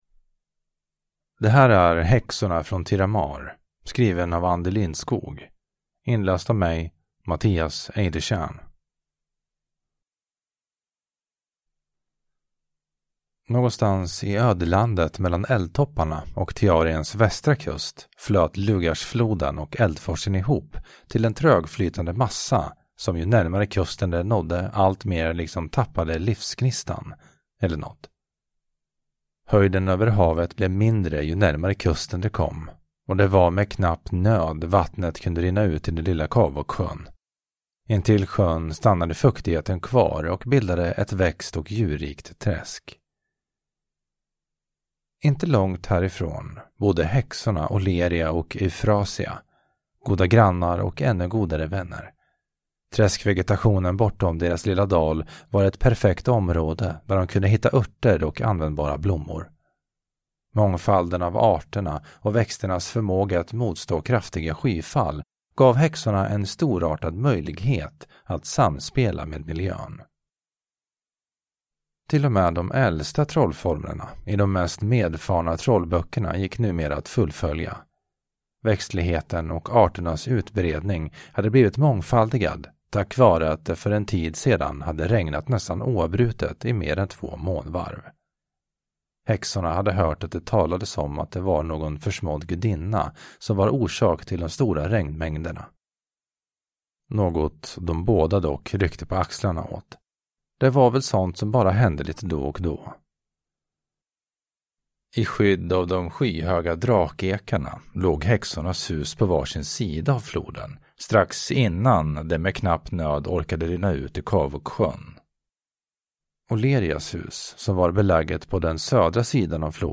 Häxorna från Thiramaar – Ljudbok